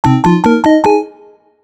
Linked obtain sound to chest opening
obtain.wav